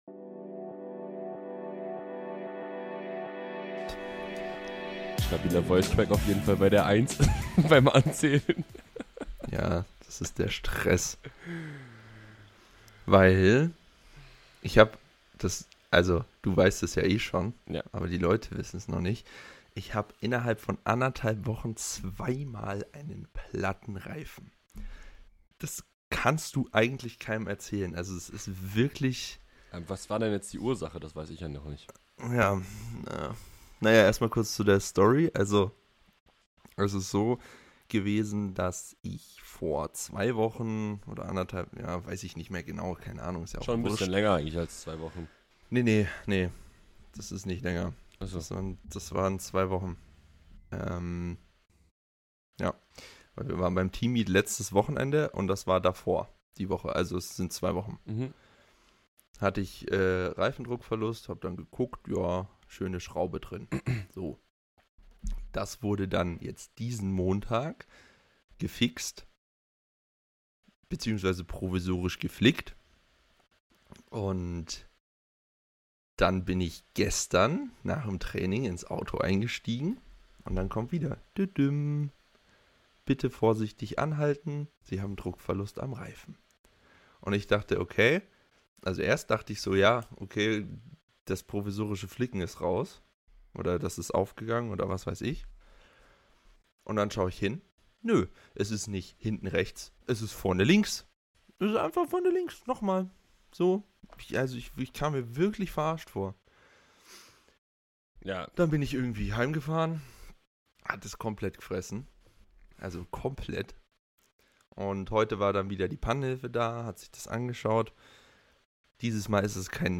Leider wieder nur zu zweit.